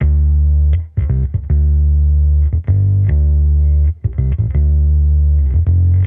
Index of /musicradar/sampled-funk-soul-samples/79bpm/Bass
SSF_PBassProc2_79D.wav